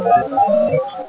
Resurrection Cemetery - 7600 Archer Ave. Chicago, Ill
arrival immediatly began a EVP recording session.
A few of them are very clear....and a few of them are not so good in quality.
The next recording abtained there was of what we feel might be that of an older woman